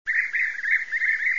Żołna pszczołojad - Merops apiaster
głosy